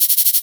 FK092PERC1-L.wav